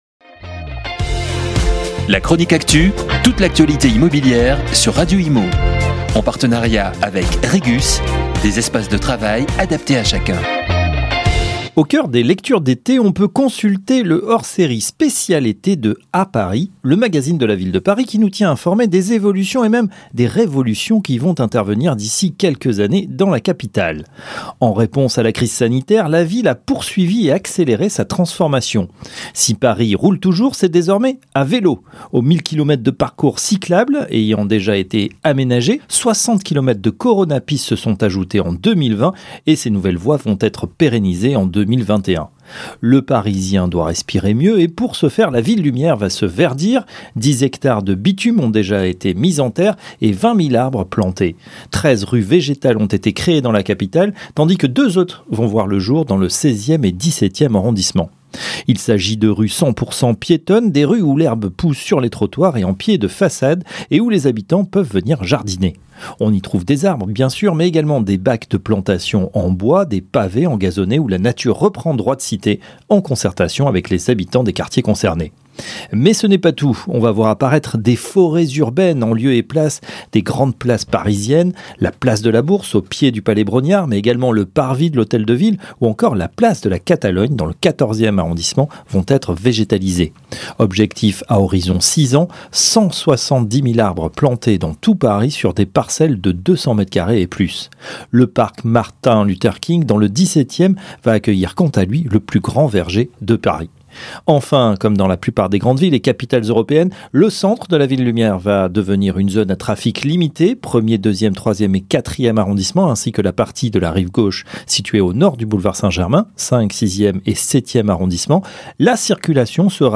La FPI publie les chiffres de l’année 2016 de son observatoire statistique national, baromètre des indicateurs avancés en logement dans toutes les régions de France. Entretien